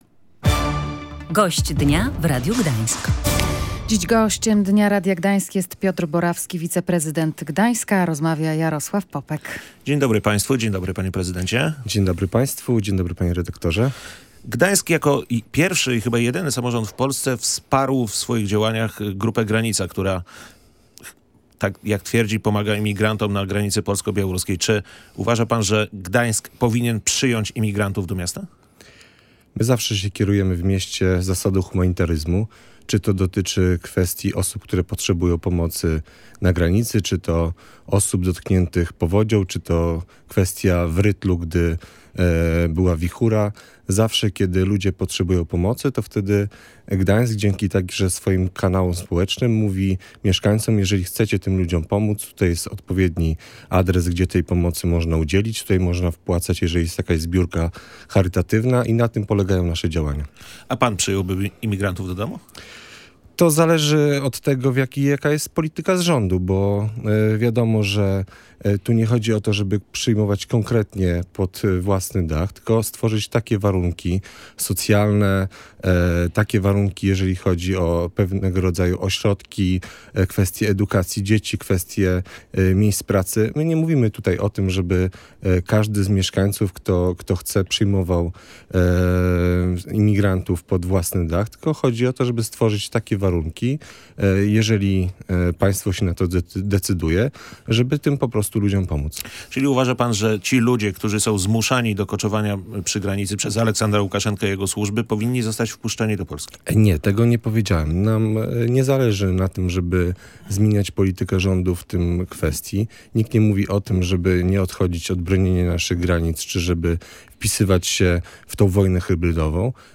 Piotr Borawski, wiceprezydent Gdańska, był gościem w studiu Radia Gdańsk.